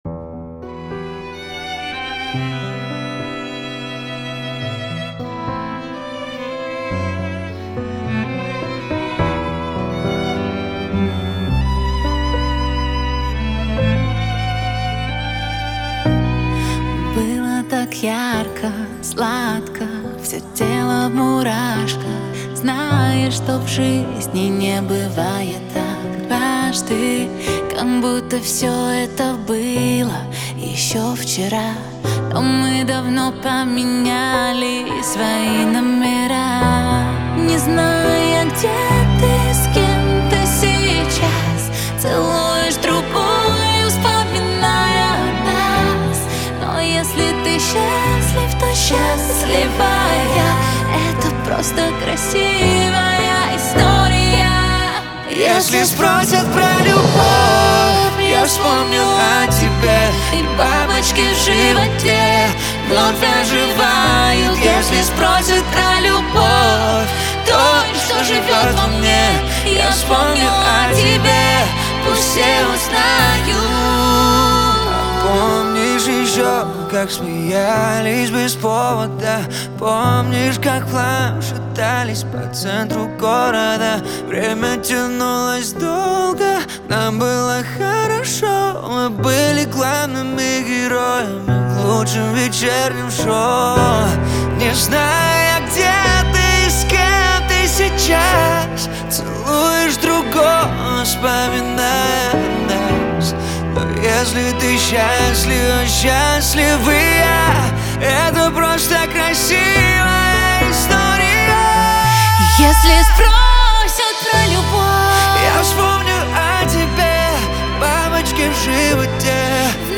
Новинки русской музыки